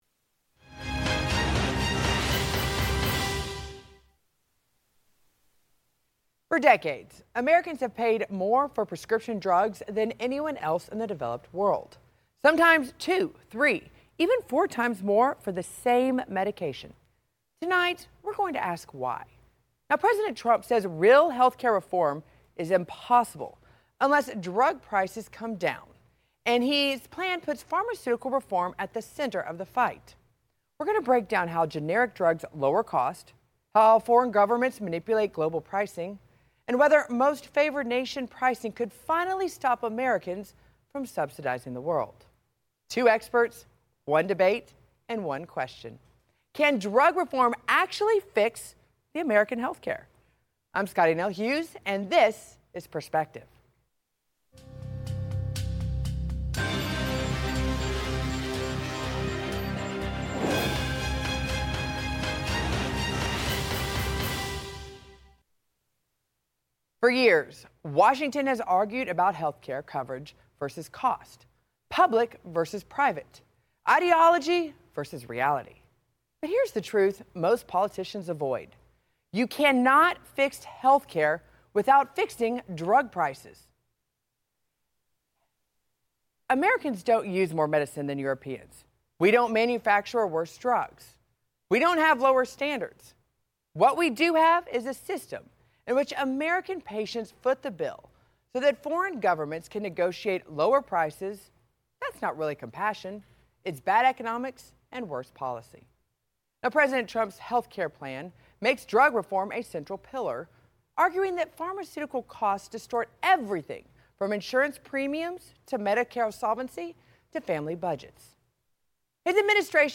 Ringing cowbells, pots and pans… a new ‘anti-corruption religion’ is emerging in Slovenia. The Zombie Church has already attracted thousands of followers and may spread beyond the tiny European enclave’s borders, its founders believe.